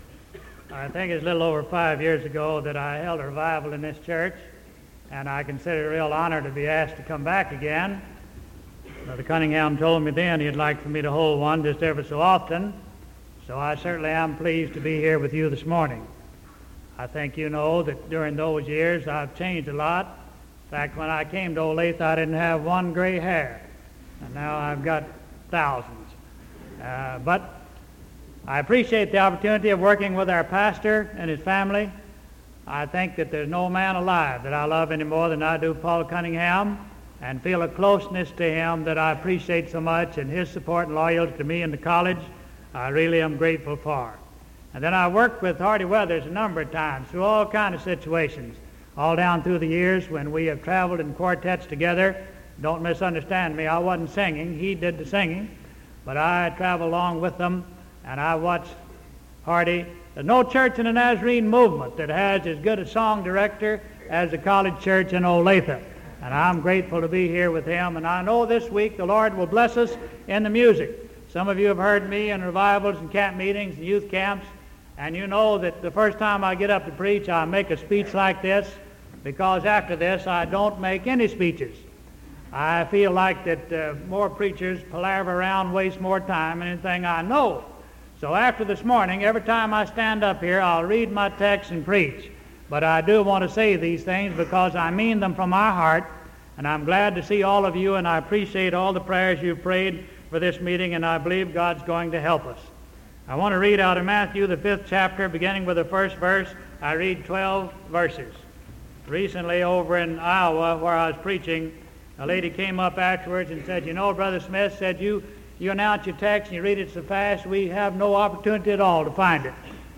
Sermon March 3rd 1974 AM